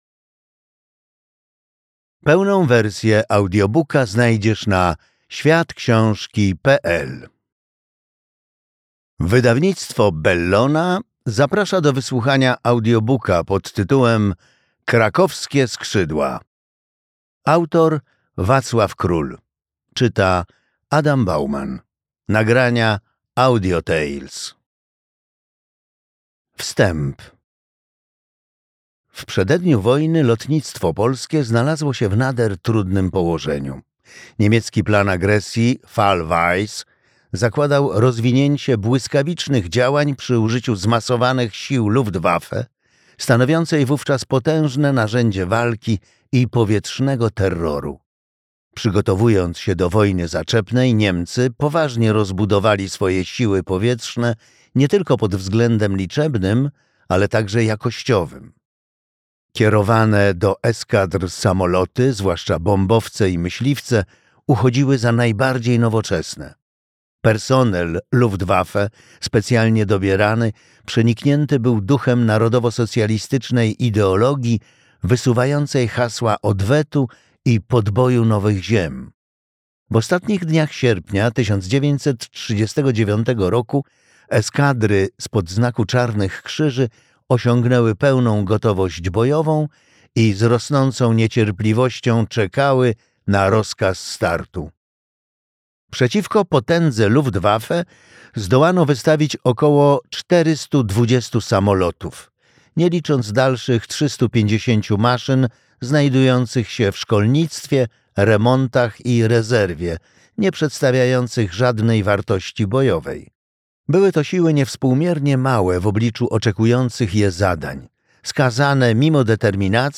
Krakowskie skrzydła - Wacław Król - audiobook